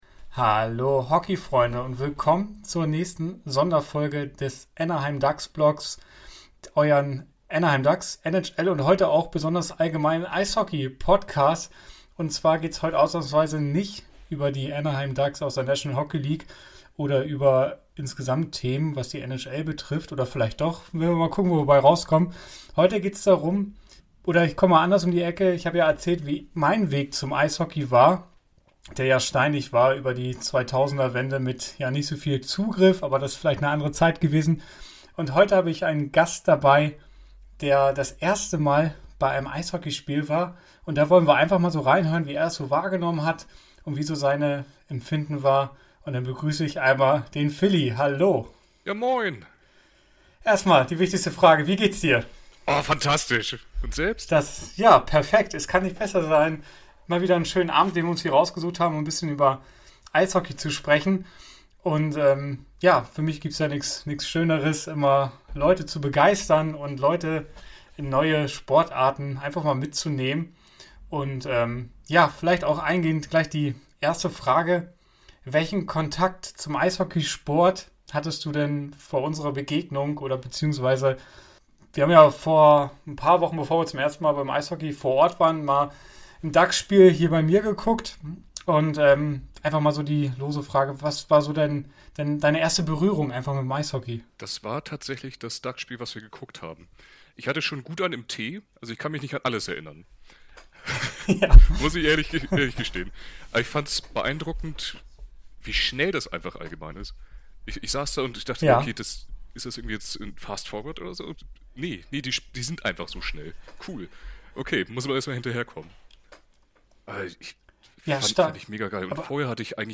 Und hat ihn der Eishockey-Virus gepackt?Ich stelle die Fragen, er liefert die Eindrücke – und gemeinsam bringen wir (vielleicht) noch mehr Menschen zum Eishockey Viel Spass!